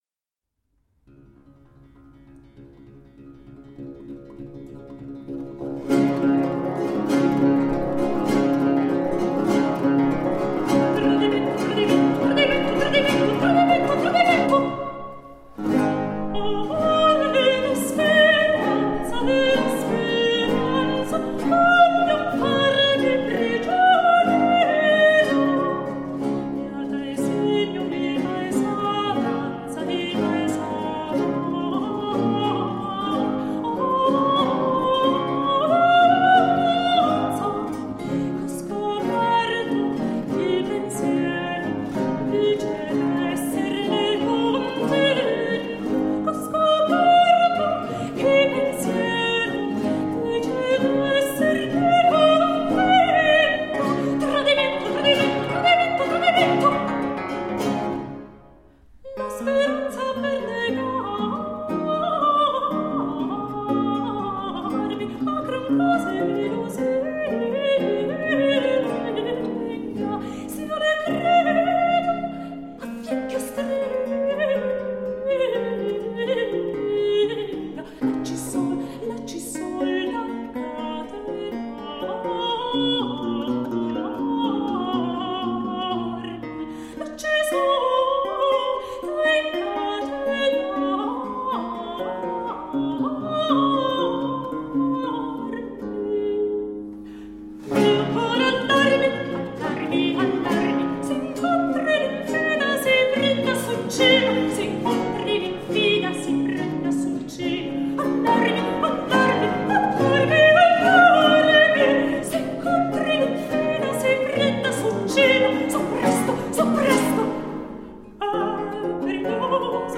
Solo lute of the italian renaissance..
soprano
Classical, Baroque, Classical Singing, Lute